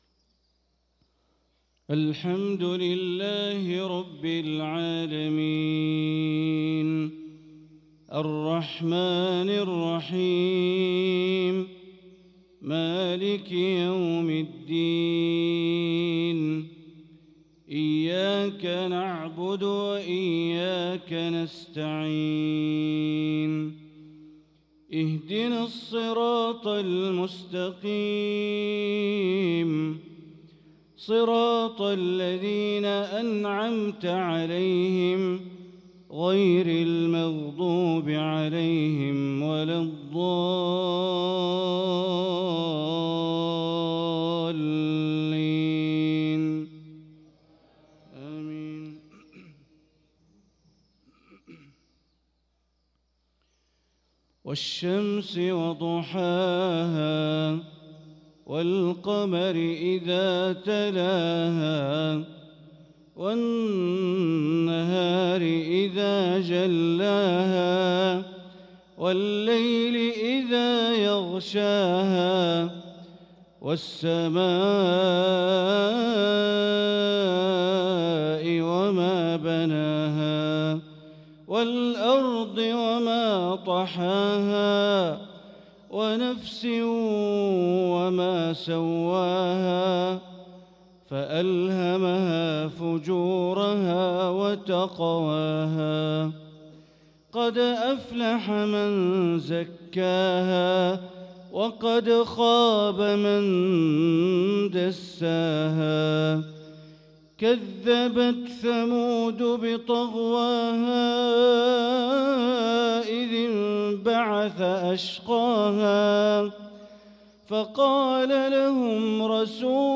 صلاة المغرب للقارئ بندر بليلة 23 رجب 1445 هـ
تِلَاوَات الْحَرَمَيْن .